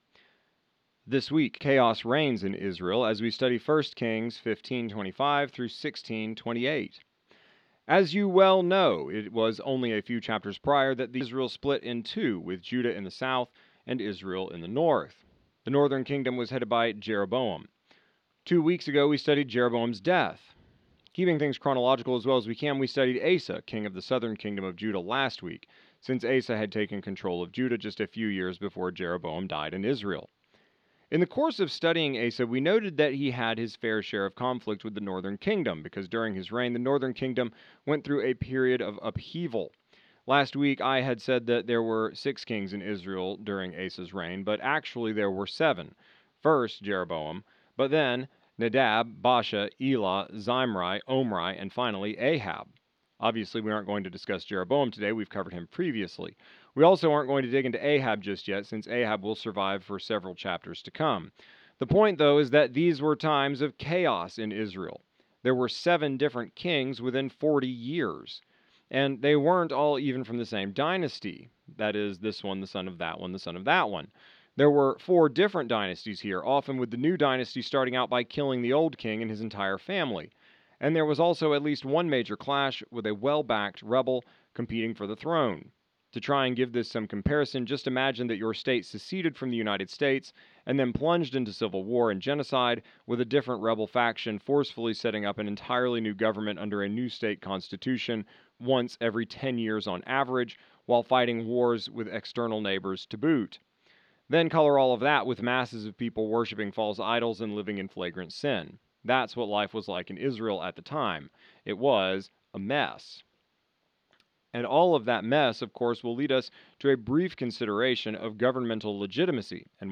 exegetical sermon series